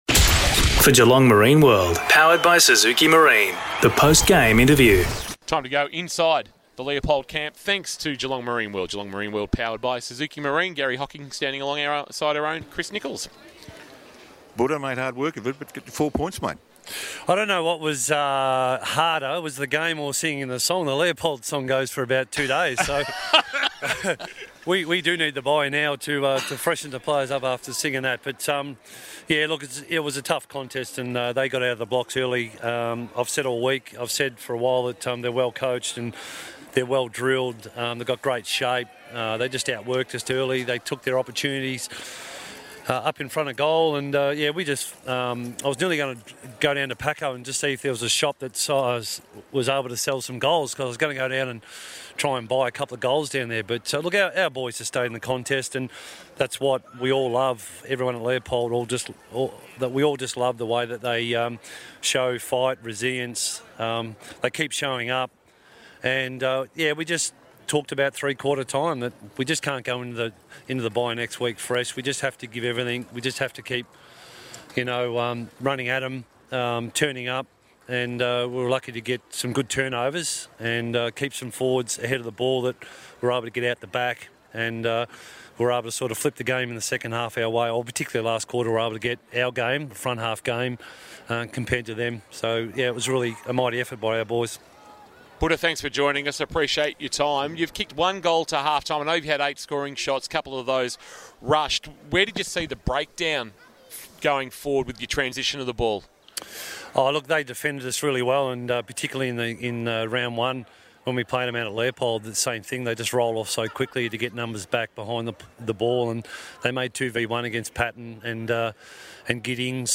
2022 – GFL ROUND 12 – GEELONG WEST vs. LEOPOLD: Post-match Interview – Garry Hocking (Leopold coach)